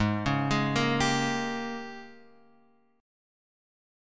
G#M7sus2sus4 chord {4 4 6 3 x 3} chord